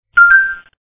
alarm2.wav